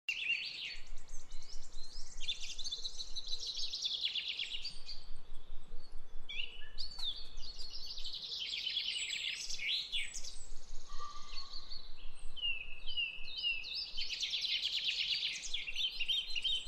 Pajaros-primaverales.mp3